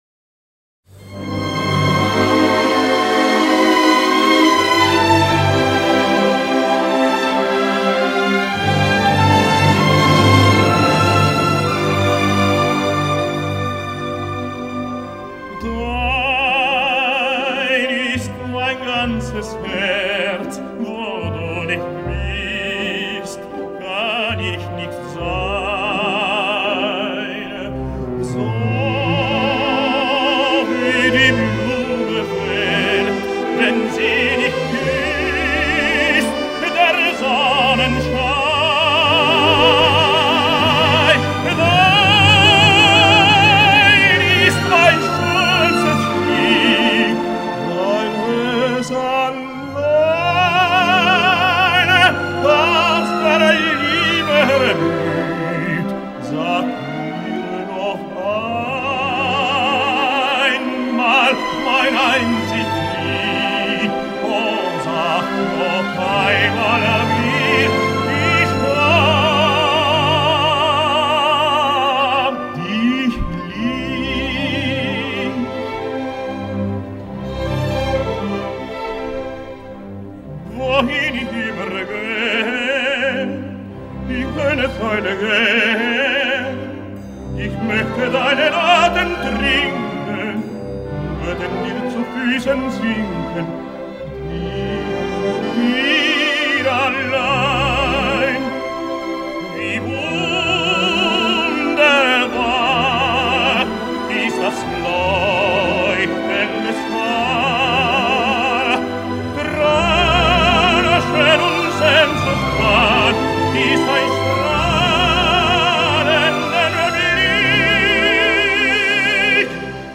其「天鹅绒」般的音色，蕴含气势磅礡的生命热焰，相信是您对抗SARS的最佳声援！